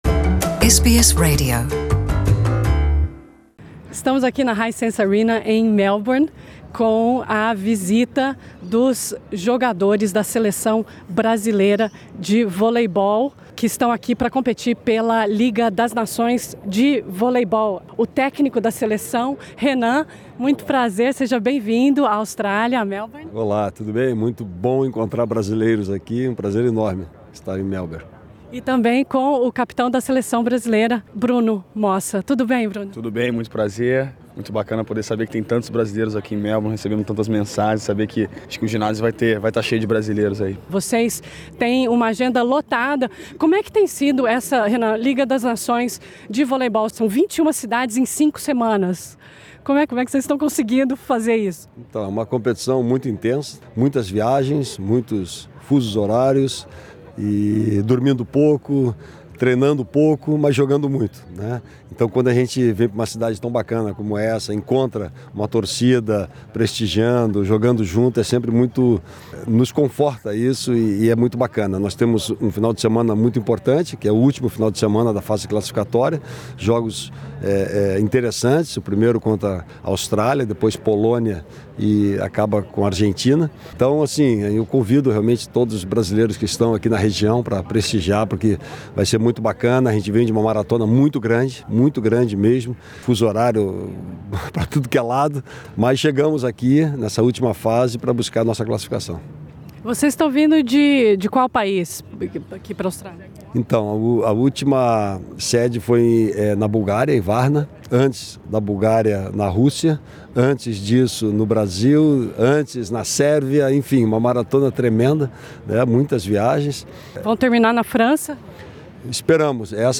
Nesse podcast, gravado antes do jogo